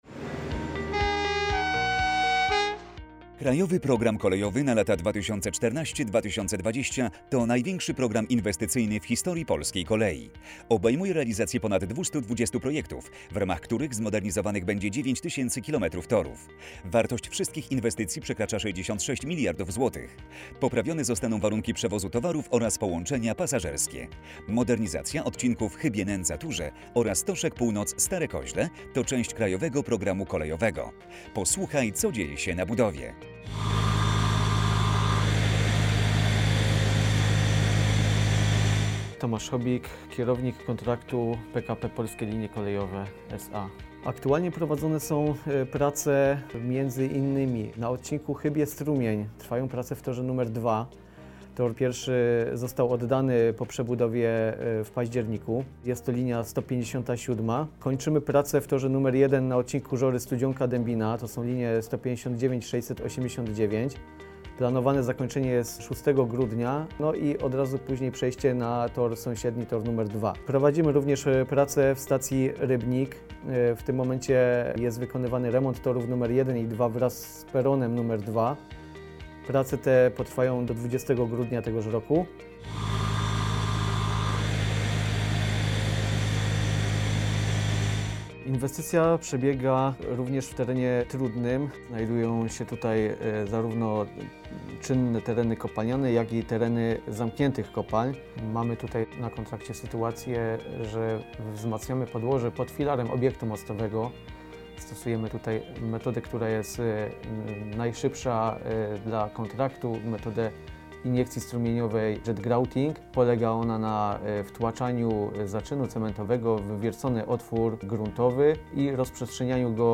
Audycje radiowe - grudzień 2017 r, odc. 4/1